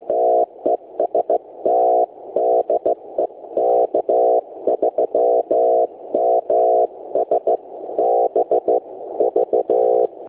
> Drift:  sometimes none, sometimes about 1 KHz over a 60 second interval.
> Tone was rough (T7 or T8) on my ICOM as well as received by my friend:
> Here?s an on the air recording: